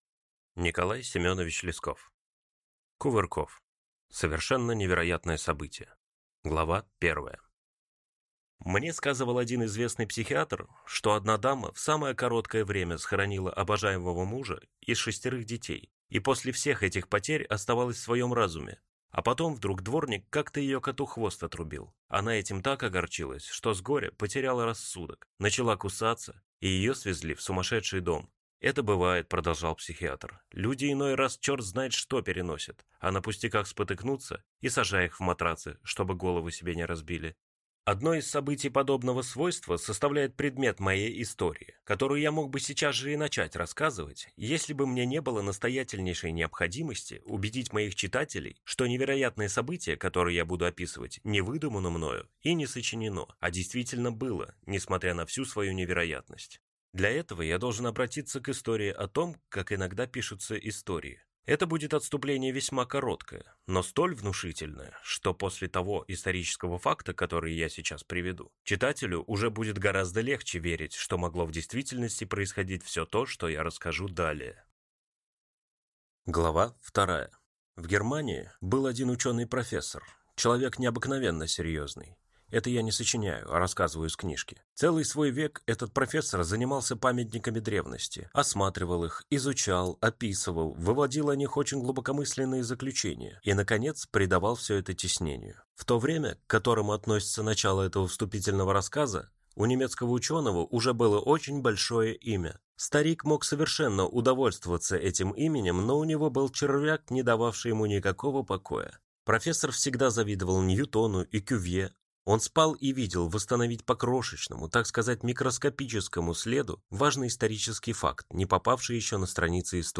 Аудиокнига Кувырков | Библиотека аудиокниг